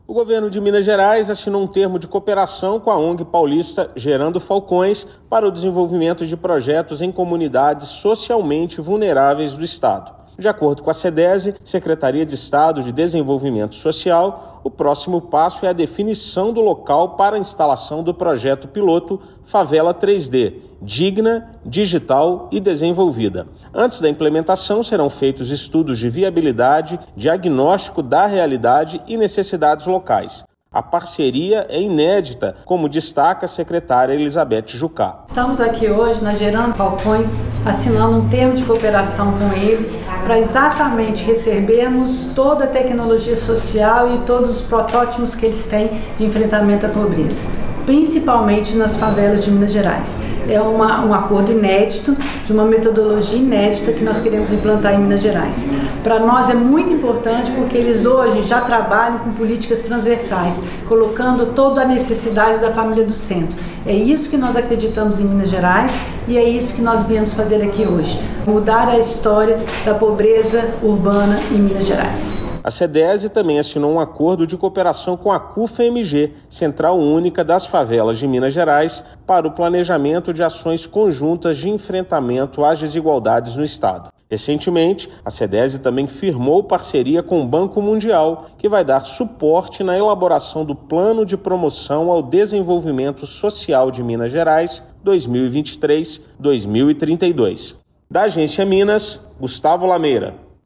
Parcerias com Gerando Falcões, Cufa-MG e Banco Mundial vai contribuir para construção do Plano de Promoção ao Desenvolvimento Social de Minas Gerais. Ouça a matéria de rádio.